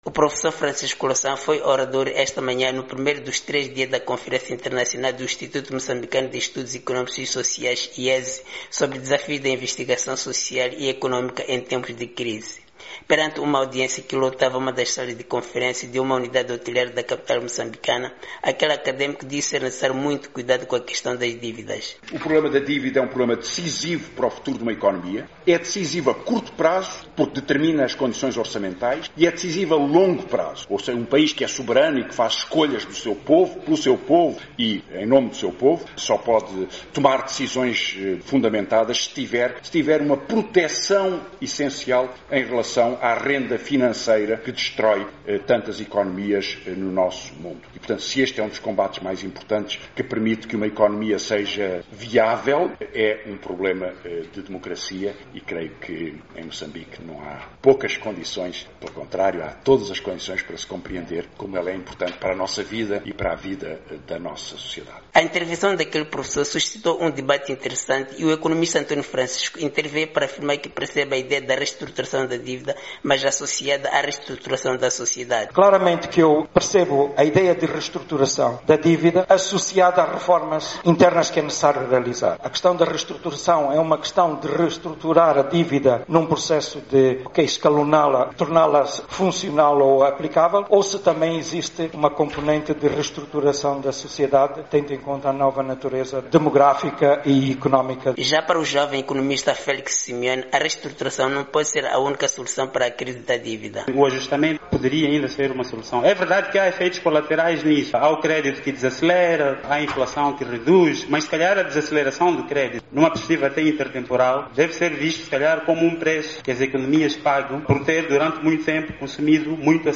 Louçã participa na conferência do X aniversáriod o IESE.
Perante uma audiência que lotava uma das salas de conferências de um hotel de Maputo aquele académico disse ser necessário muito cuidado com a questão das dívidas.